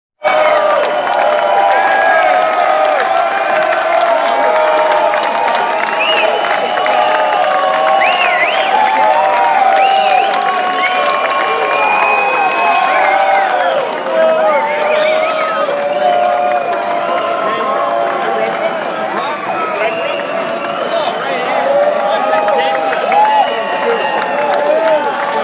Applause Applause!
Pretend you’re a rock star and click the link to hear the applause.
applause-applause.m4a